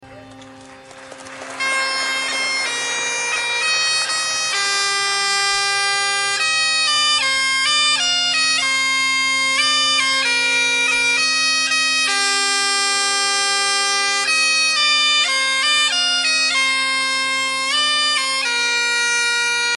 Bagad Cap Caval GHB tuning
Here is an excerpt of the recording so you can here the low F#:
Great Highland Bagpipe Solo
bagadtuning.mp3